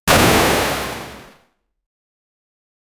Flag Checkpoint Reach video game, happy and lively
flag-checkpoint-reach-vid-zz3iuycu.wav